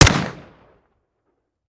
Home gmod sound weapons mpapa5
weap_mpapa5_fire_plr_01.ogg